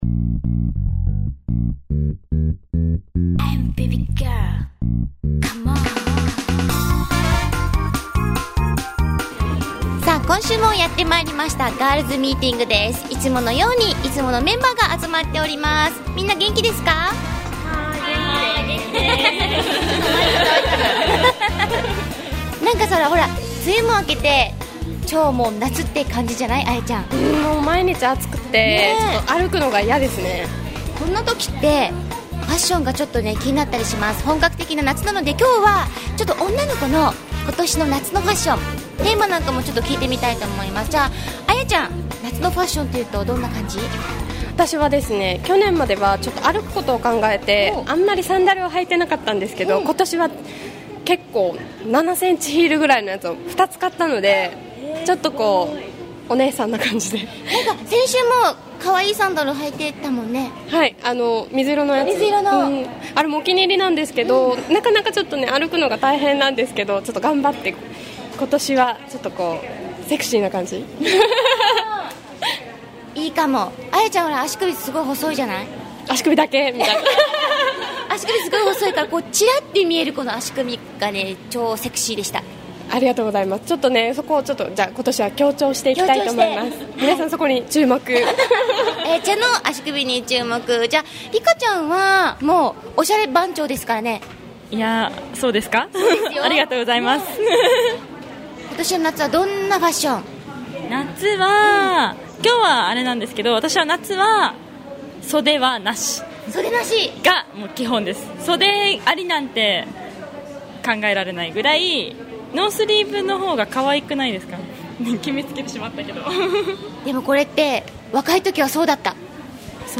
今週もいつものカフェで・・・ガールズパワー炸裂